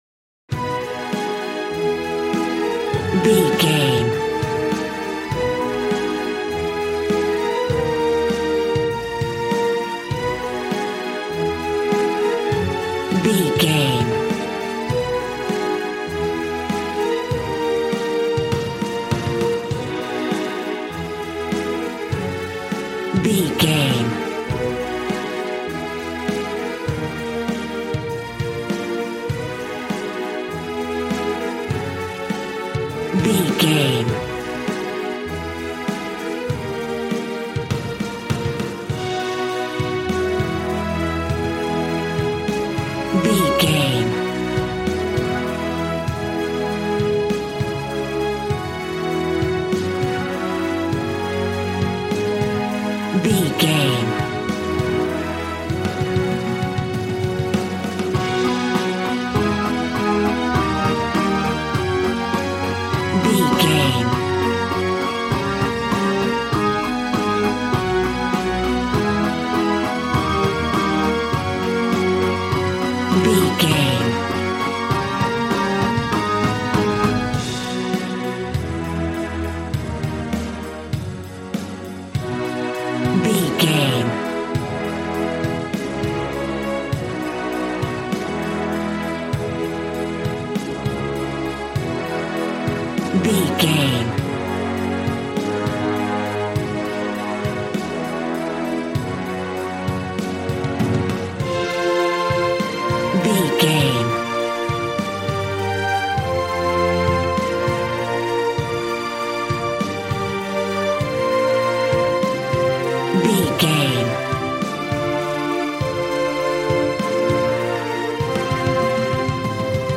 Aeolian/Minor
A♭
dramatic
strings
violin
brass